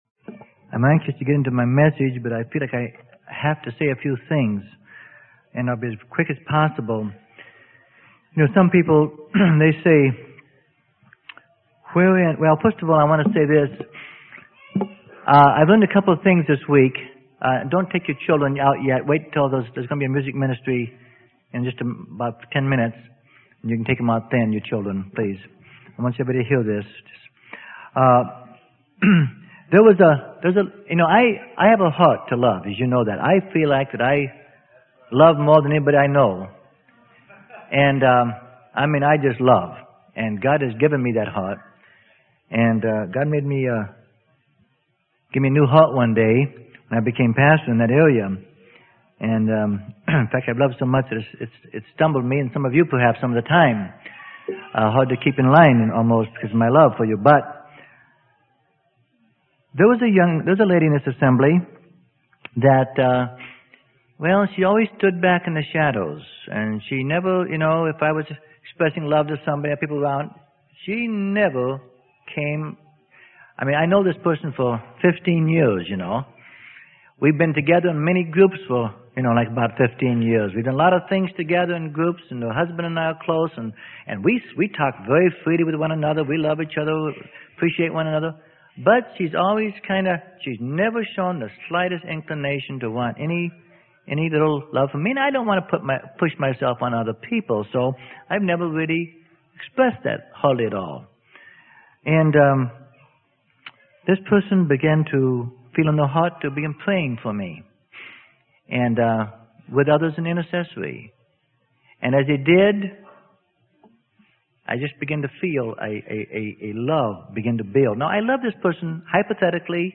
Sermon: How We Handle God's Judgment is Important - Freely Given Online Library